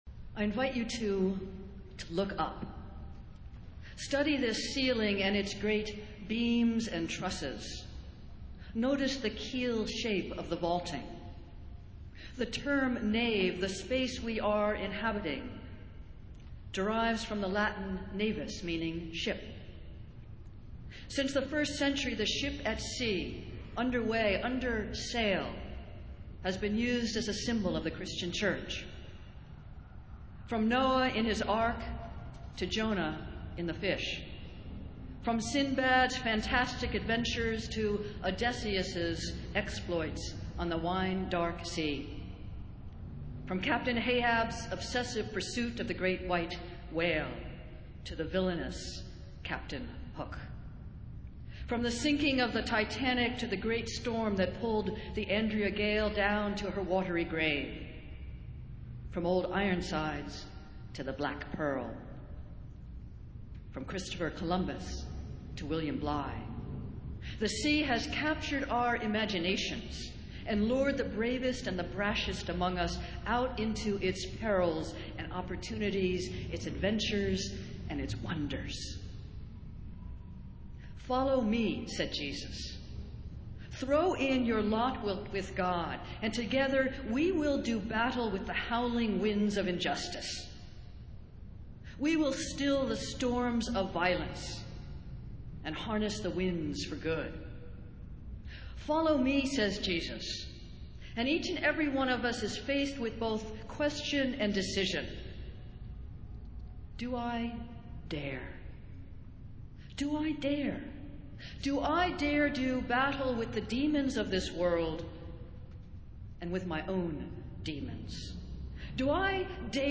Festival Worship - Vision Sunday